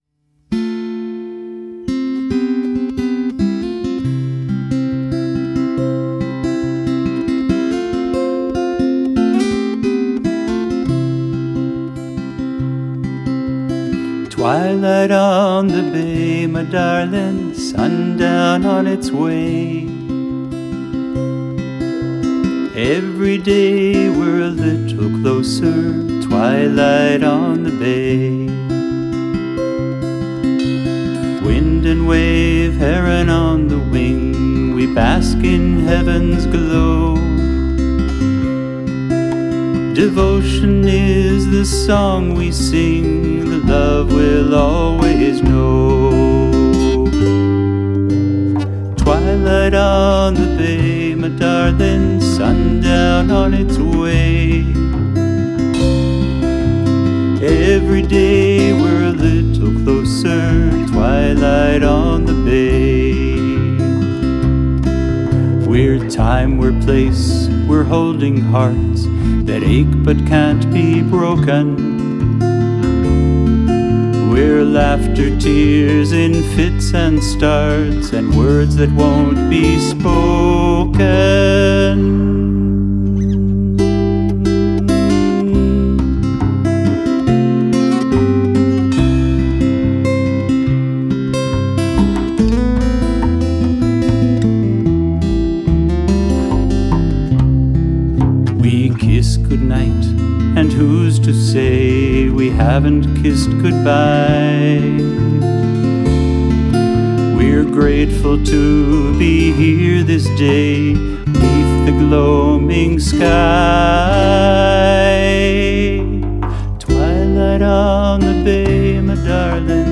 vocals, guitar, kazoo
bass, keyboards, mandolin
drums, djembe, goat hooves, tambourine
saxes